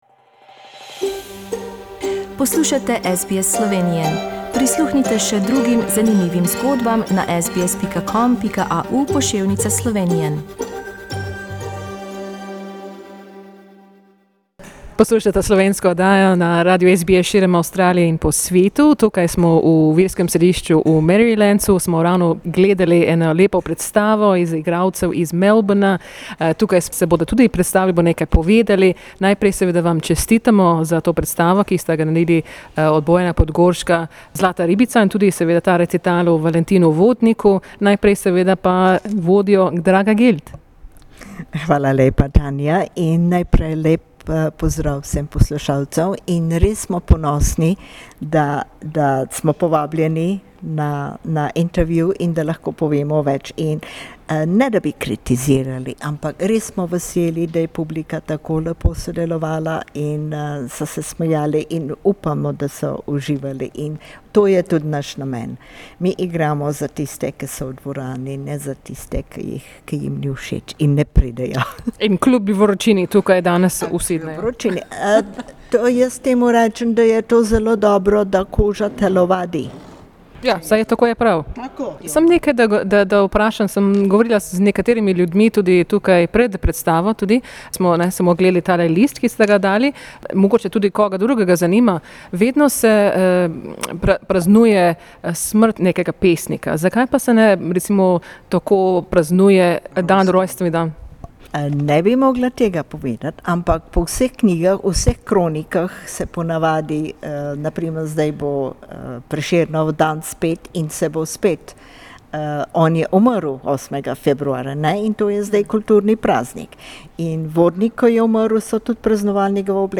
On Sunday 2nd February, Drama Group Melbourne performed at St Raphael's Slovenian mission in Merrylands. They spoke about Slovenian poet Valentin Vodnik as well as the comedy Zlata ribica.